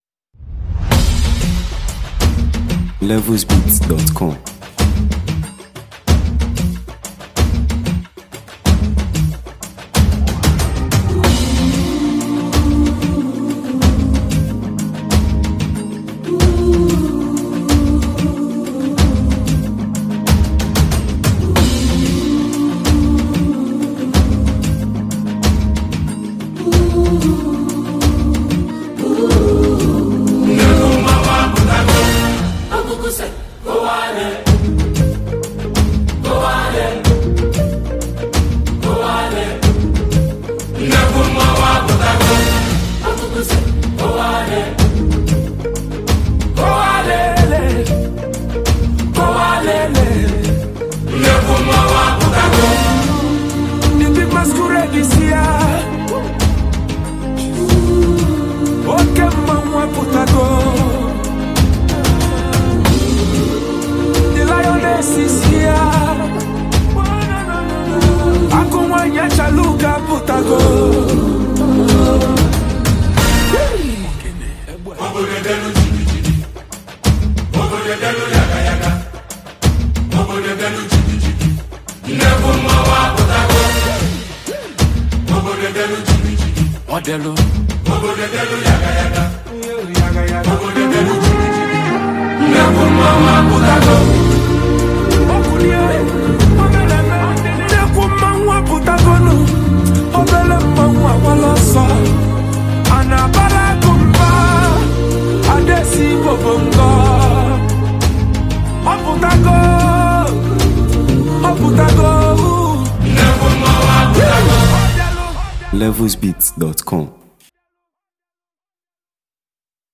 Renowned Nigerian highlife maestro and gifted songwriter